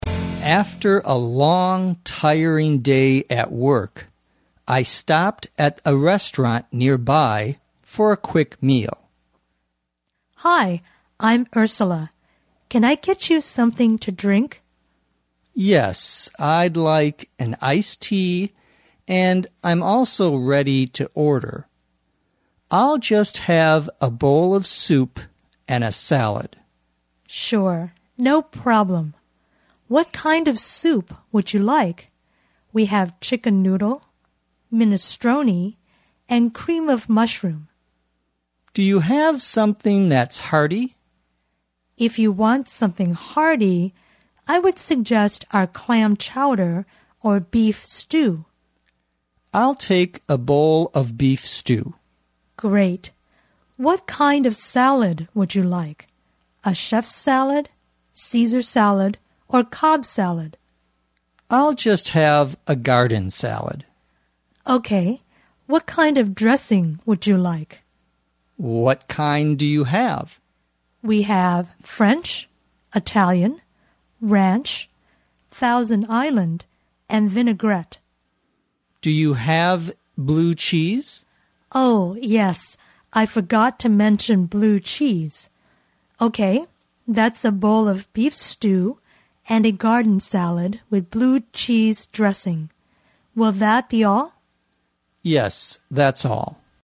地道美语听力练习:点餐 Ordering Soups and Salads